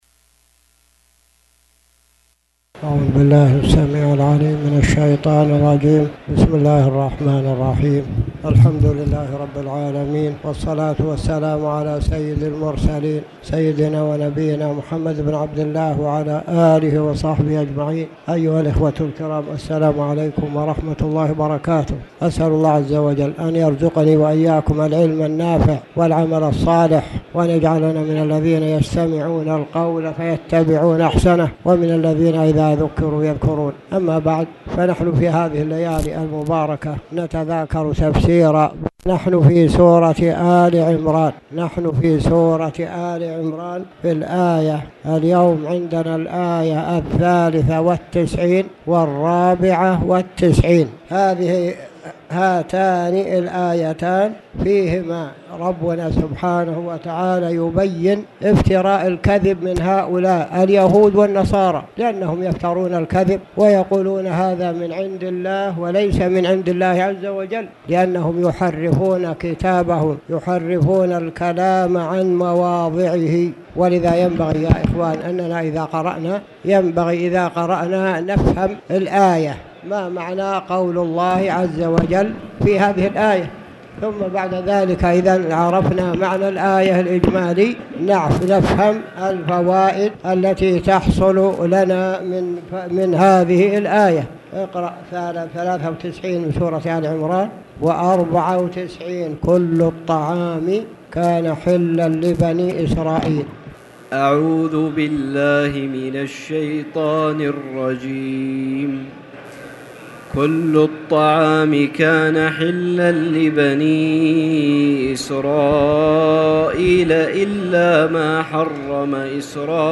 تاريخ النشر ٢٧ رمضان ١٤٣٨ هـ المكان: المسجد الحرام الشيخ